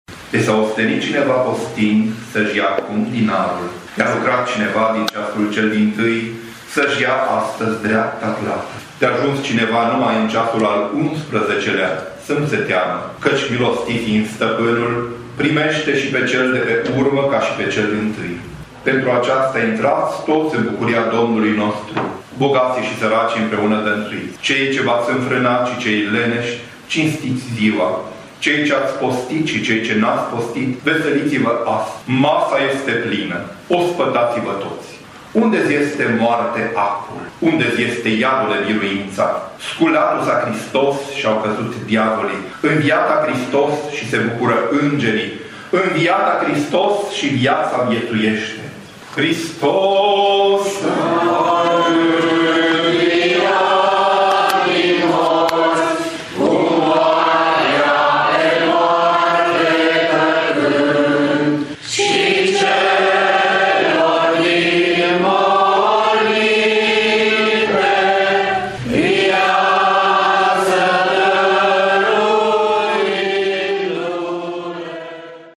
Slujba a fost transmisă în direct de postul nostru de radio de la două biserici din oraș, de la ora 23,00 până la 3,00 dimineața.
creștinii au ieșit pe străzi cu lumânări în mâini, cântând Imnul Învierii.